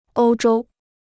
欧洲 (oū zhōu): Europe; European.